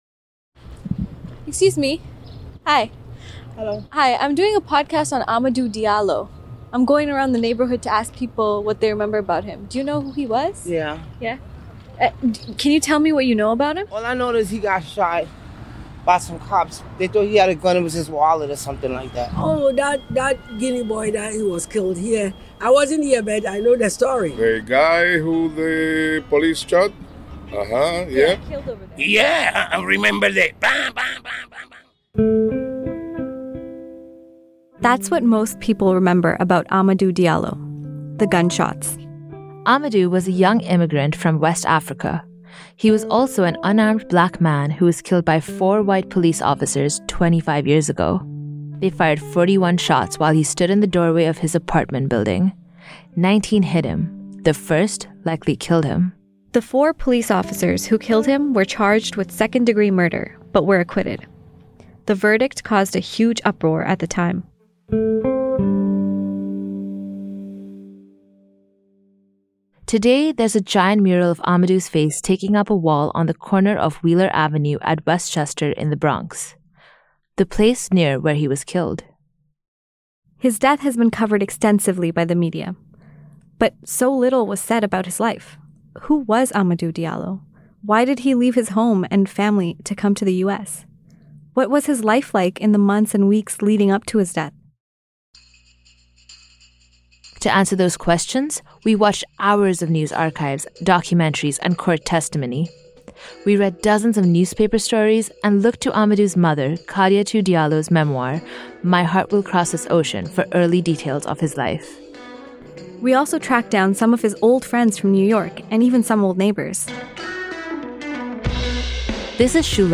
In “Boli”, we dive into Amadou’s life – from his dreams to his time in New York. We hear from neighbors and friends who go down memory lane to tell us his side of the story.